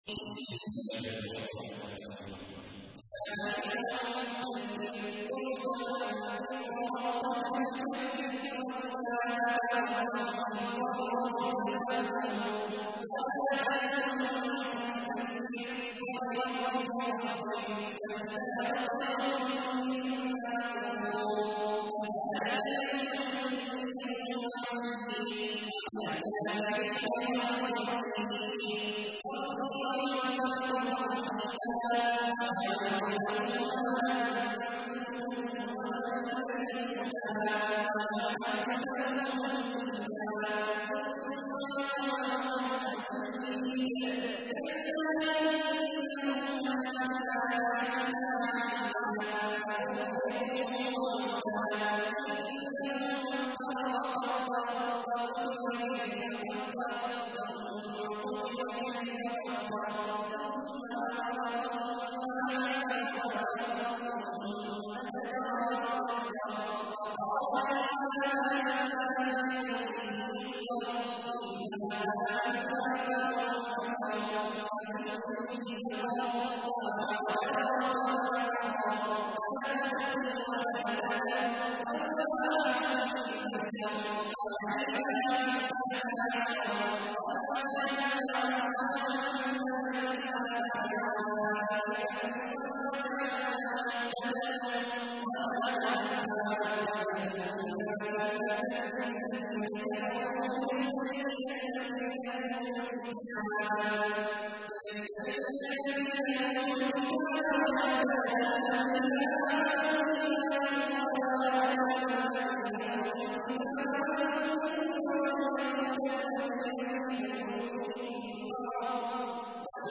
تحميل : 74. سورة المدثر / القارئ عبد الله عواد الجهني / القرآن الكريم / موقع يا حسين